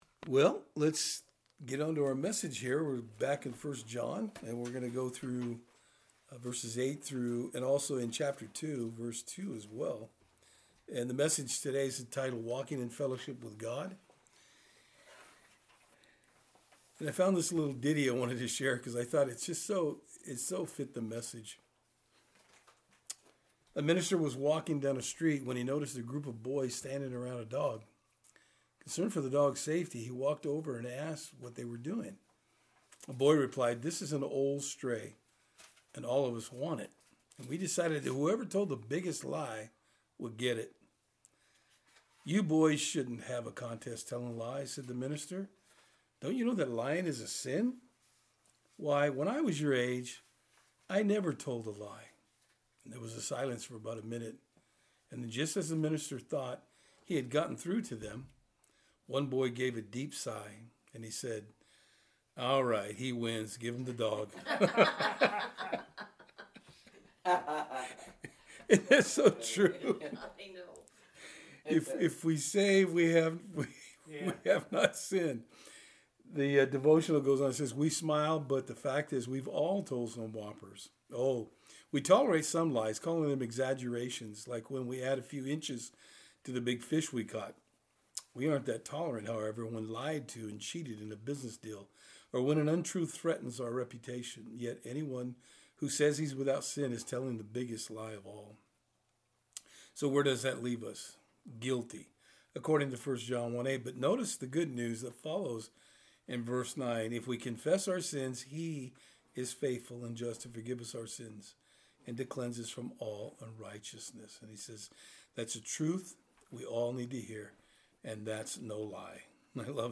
Service Type: Thursday Eveing Studies